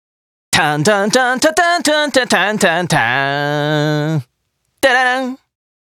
Chat Voice Files